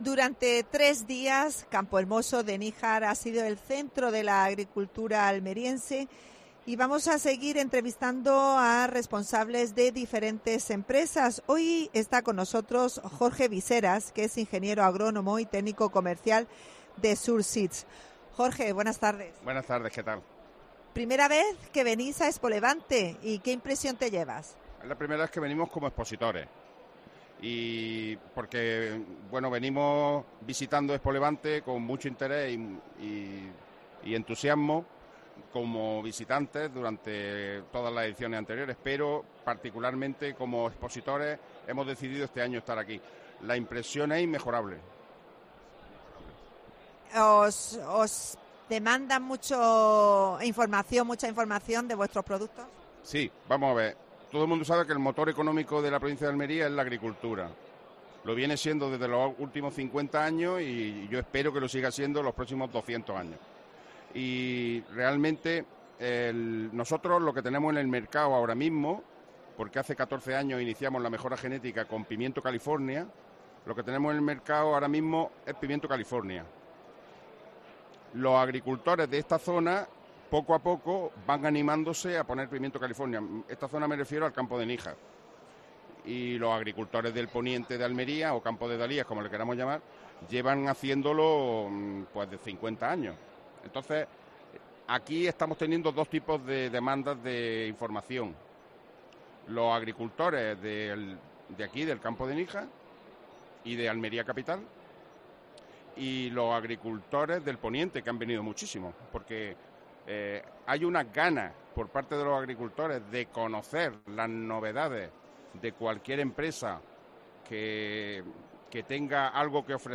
Especial ExpoLevante.